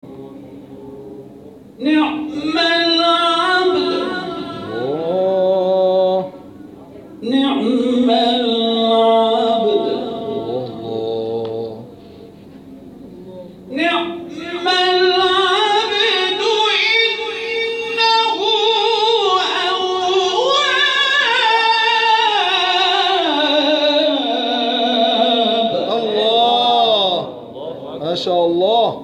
گروه فعالیت‌های قرآنی: جدیدترین مقاطع صوتی تلاوت شده توسط قاریان ممتاز کشور را می‌شنوید.